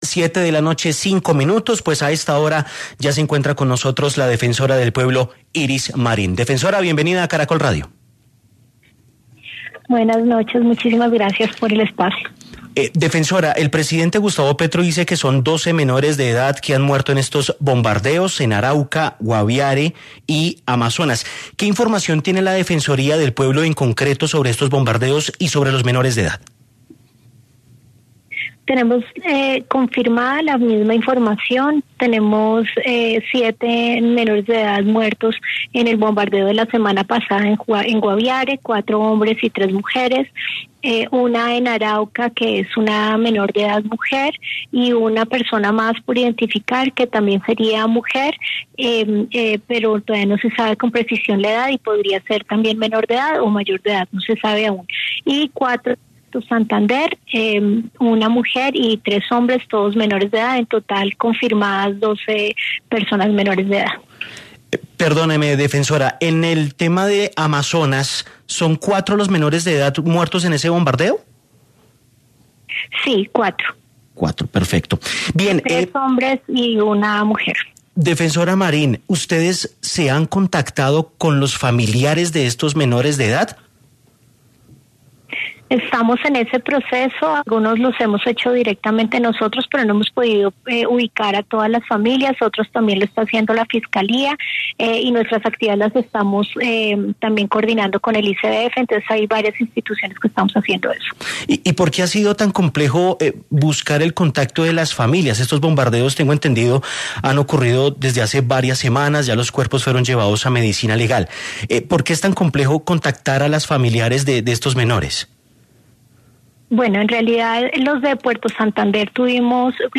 En diálogo con Caracol Radio, la Defensora del Pueblo, Iris Marín, insistió en que se deben suspender los bombardeos contra organizaciones criminales por la presencia de menores de edad en campamentos ilegales.